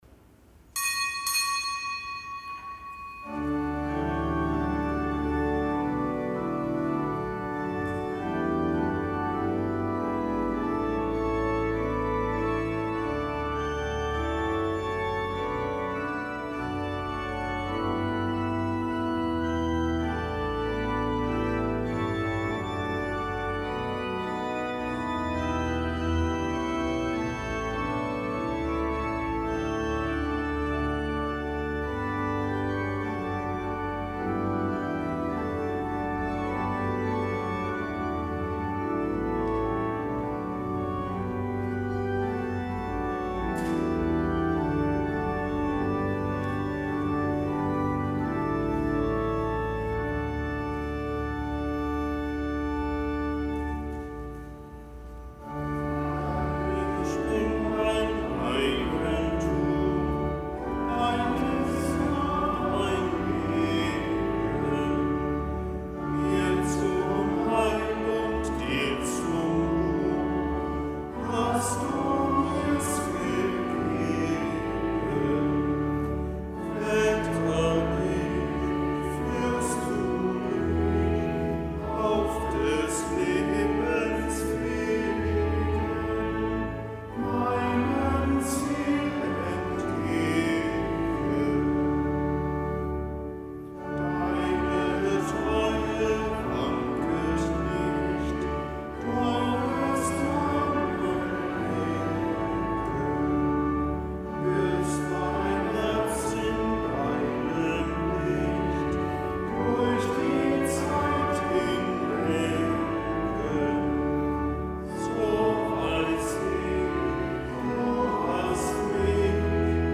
Kapitelsmesse aus dem Kölner Dom am Gedenktag Hl. Bonaventura